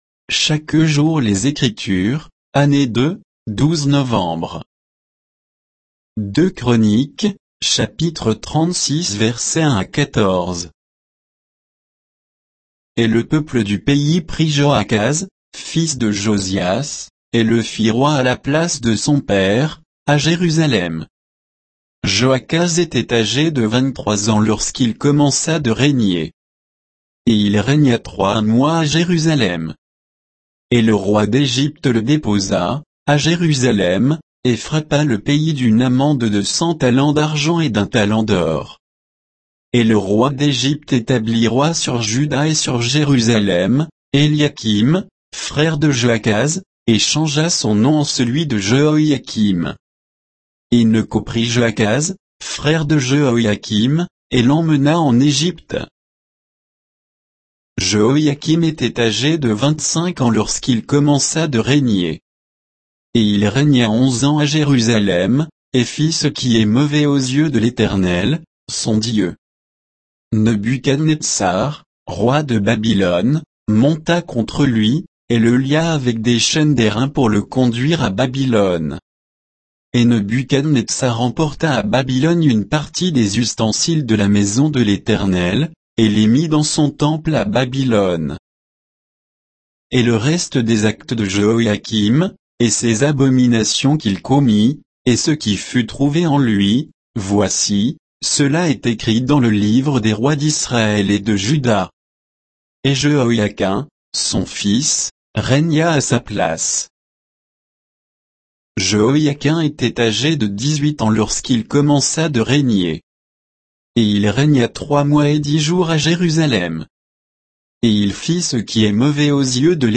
Méditation quoditienne de Chaque jour les Écritures sur 2 Chroniques 36